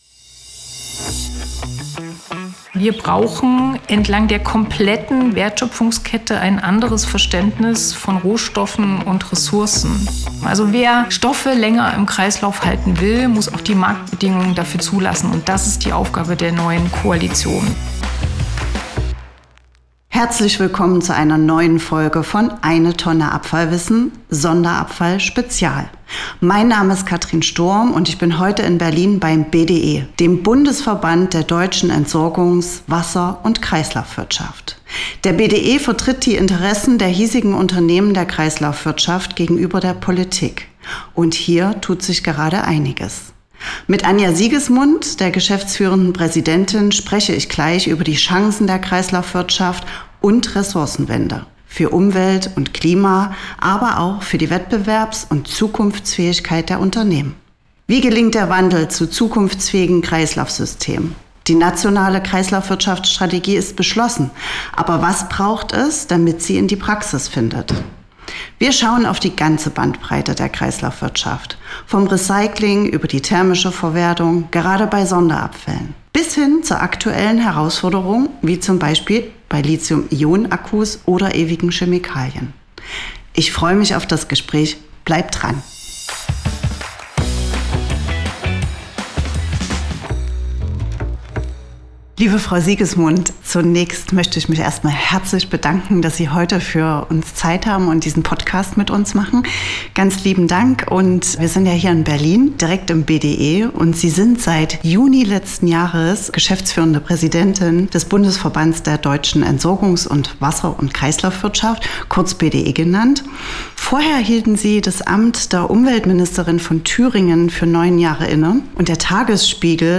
Sonderabfallwissen spricht mit ihr im Haus der Kreislaufwirtschaft in Berlin über ihre Arbeit.